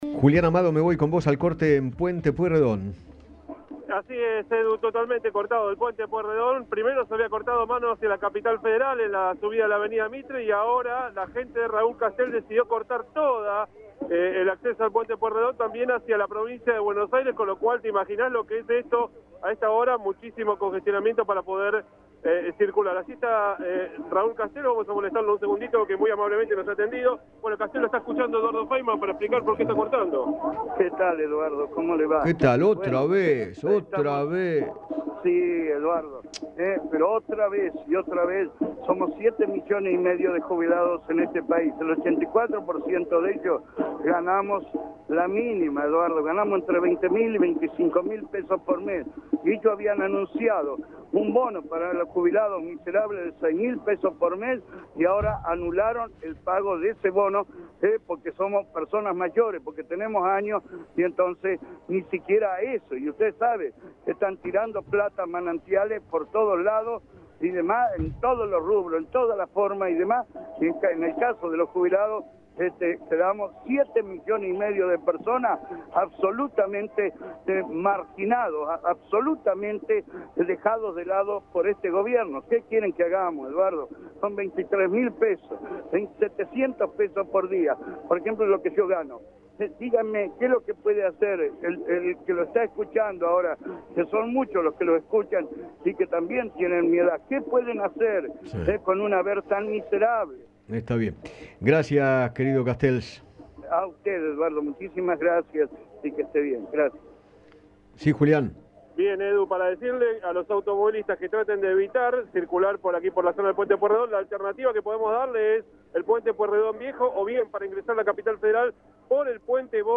Raúl Castells, dirigente social, conversó con Eduardo Feinmann sobre las razones que lo llevaron a realizar otro corte en Puente Pueyrredón junto a más piqueteros.